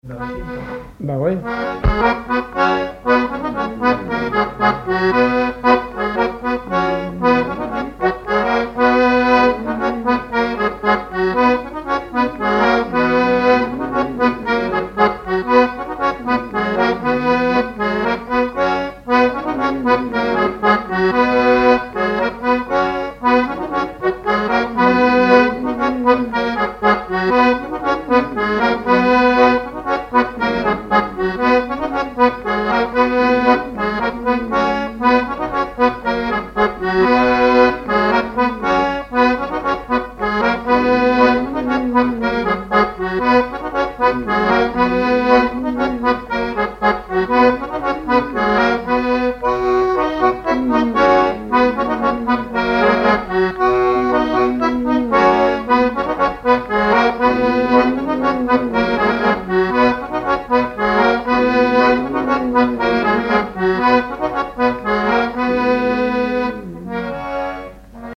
Couplets à danser
branle : courante, maraîchine
Répertoire sur accordéon diatonique
Pièce musicale inédite